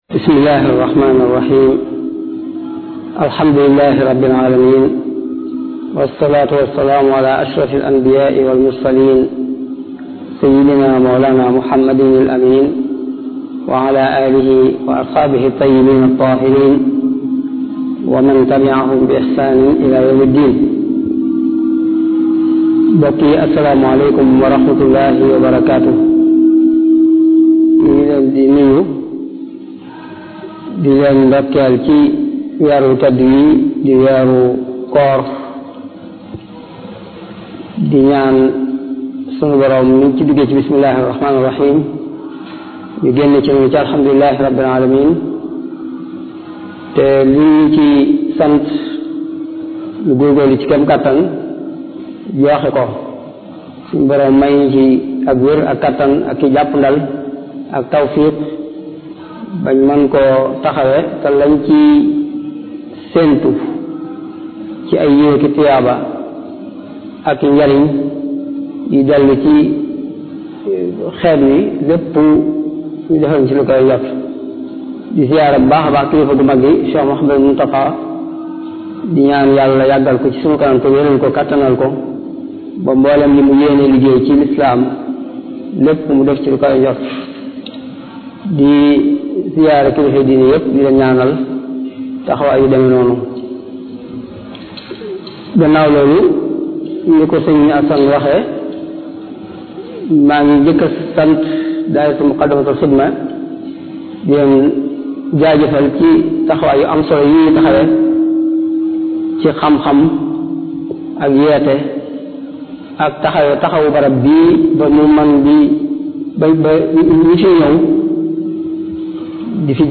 Ramadan 2022 - 1443H : Ecouter et télécharger les conférences de la Grande Mosquée de TOUBA organisé par le dahira Moukhaddimatoul Khidma Playlist